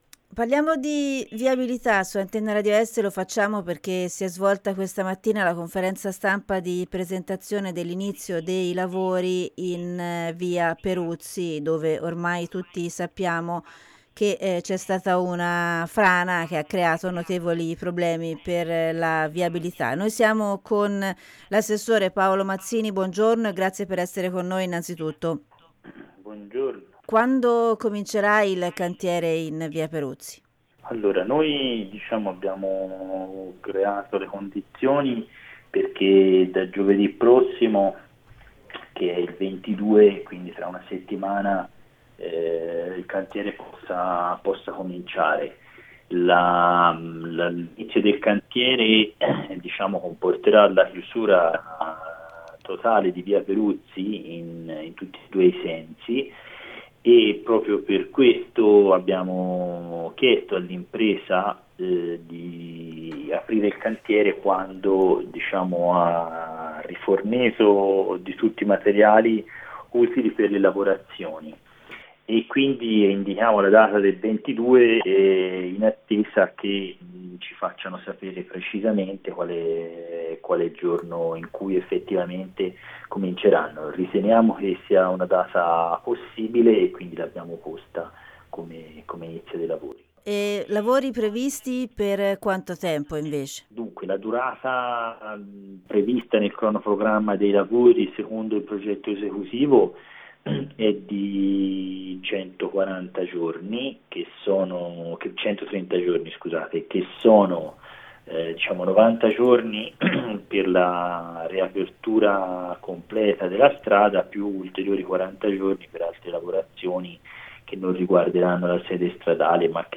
Ai microfoni di ARE l’assessore ai lavori pubblici Paolo Mazzini